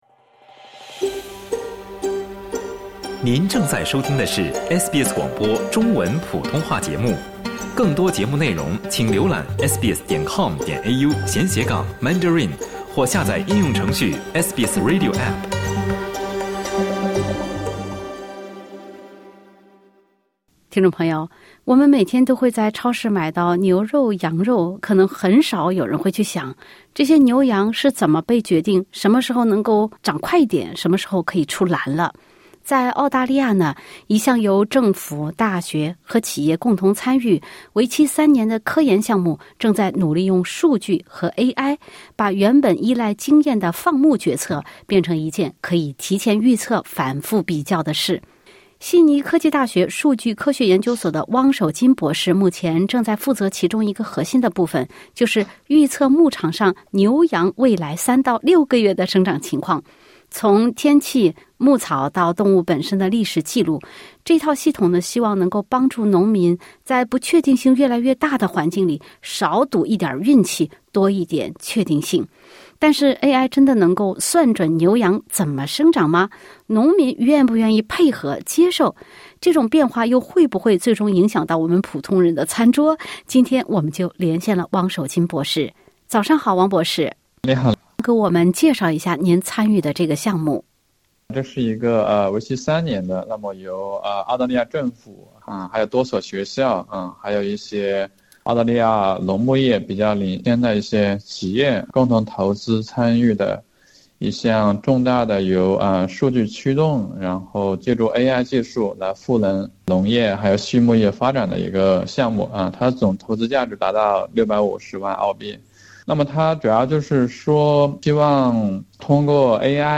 下面请听本台记者带来的采访报道。